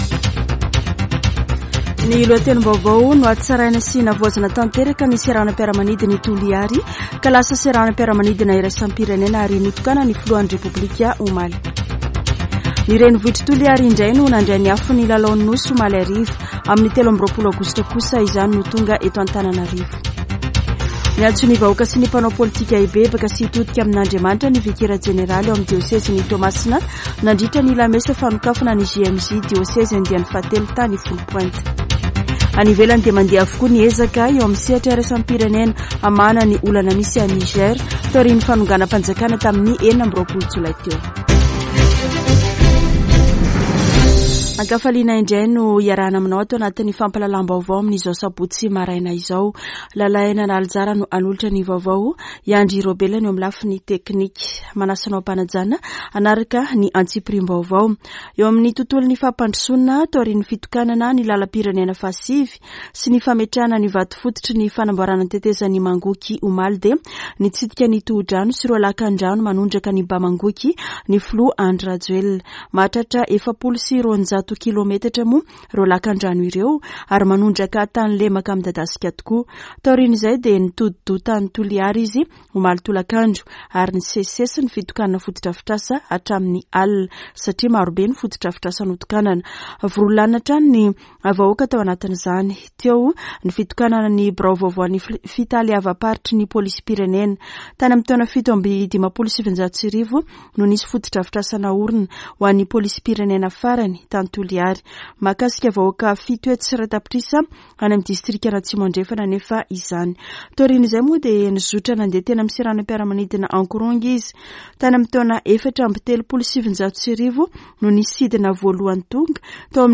[Vaovao maraina] Sabotsy 19 aogositra 2023